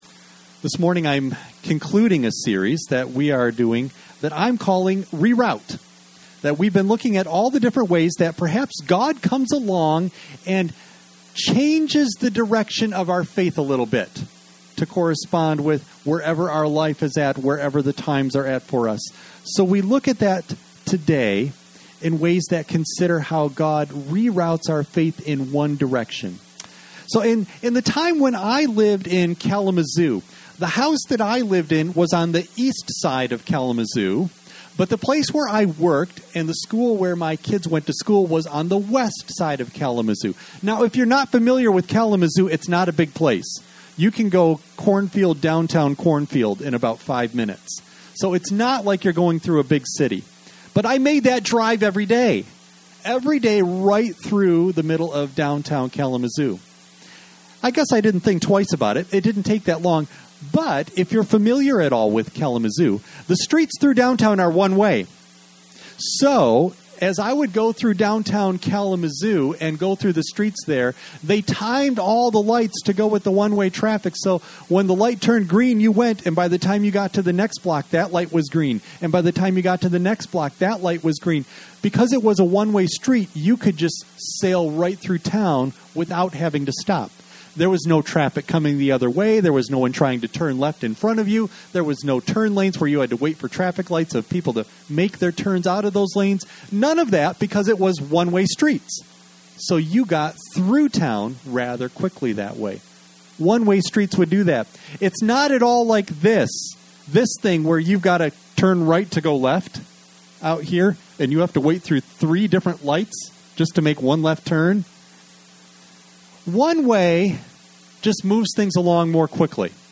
You may download and print the BULLETIN for this service as well as sermon NOTES for children from the Download Files section at the bottom of this page Worship Service August 30 Audio only of message